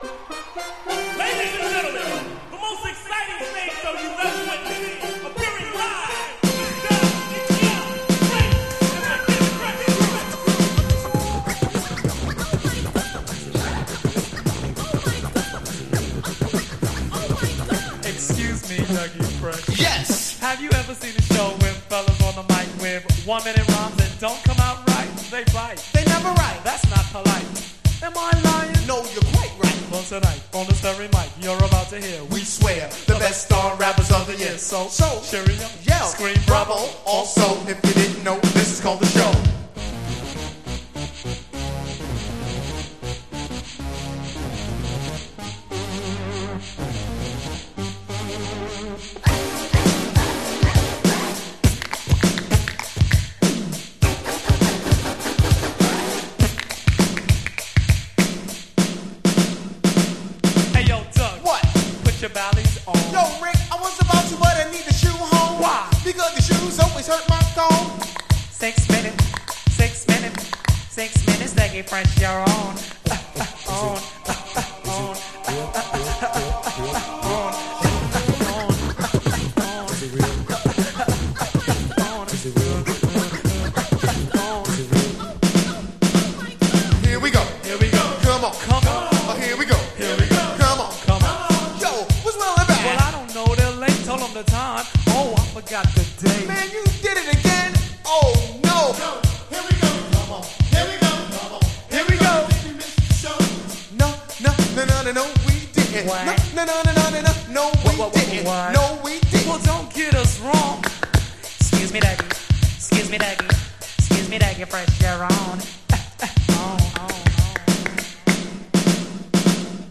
Genre: Rap